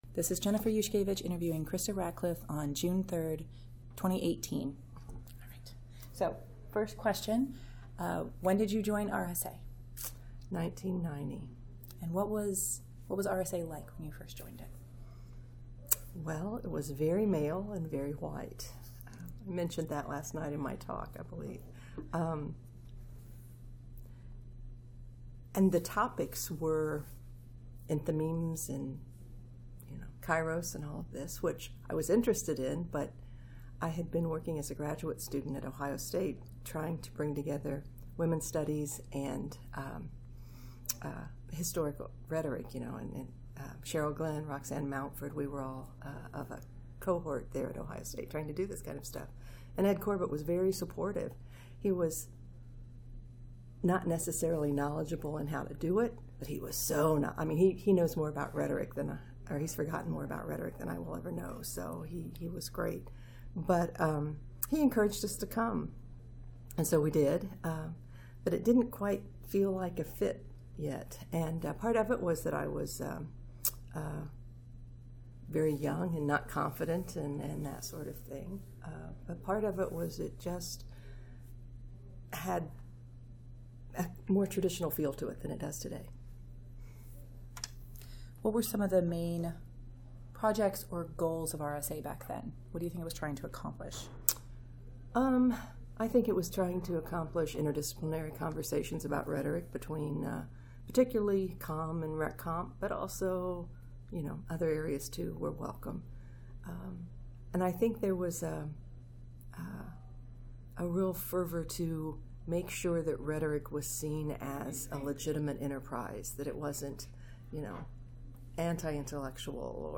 Oral history interview
2018 RSA Conference in Minneapolis, Minnesota